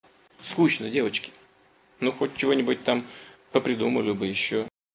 • Качество: высокое
Фраза Путина Ты молодец мальчик не испугался